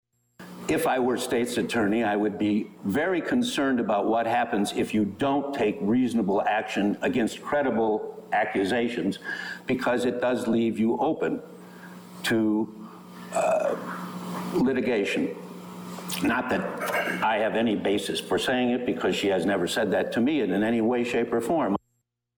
(Former State’s Attorney Richard Doyle)